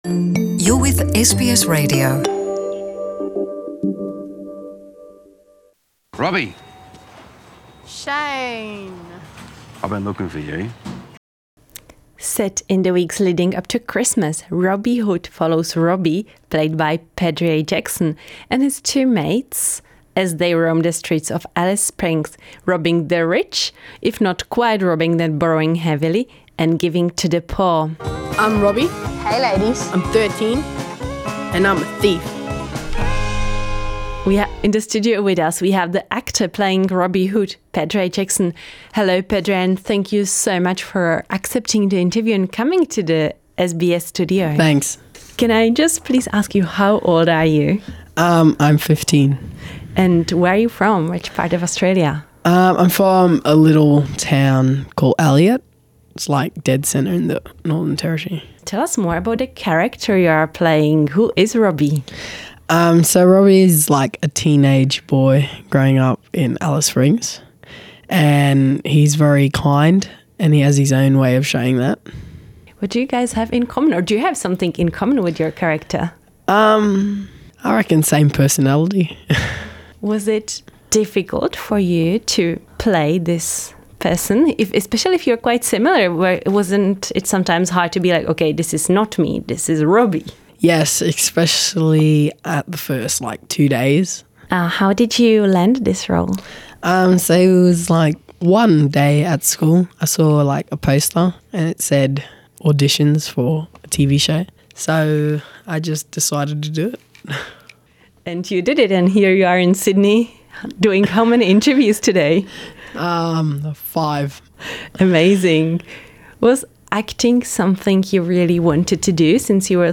NITV Radio studios